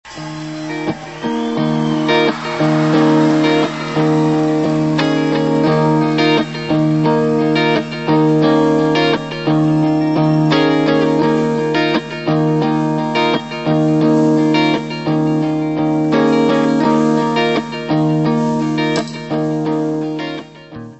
baixo, voz
guitarra, teclas, voz.
Music Category/Genre:  Pop / Rock